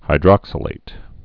(hī-drŏksə-lāt)